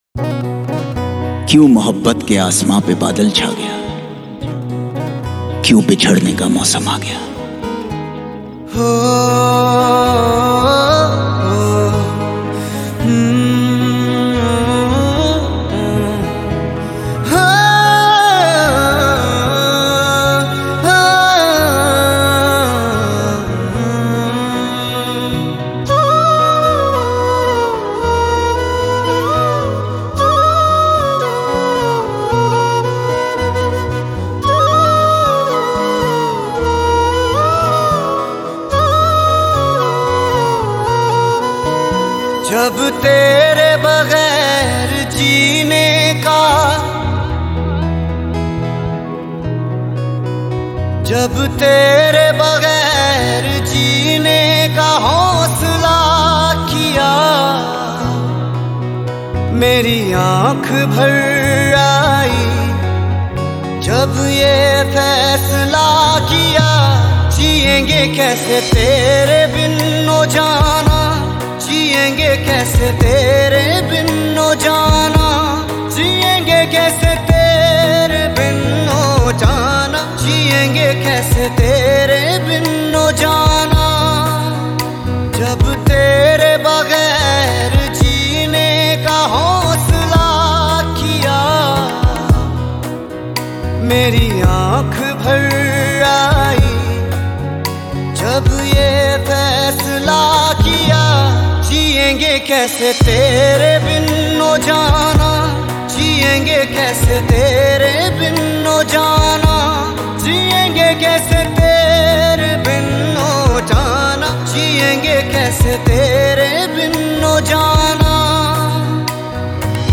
Indian POP Mp3 Song